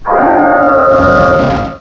pokeemerald / sound / direct_sound_samples / cries / reshiram.aif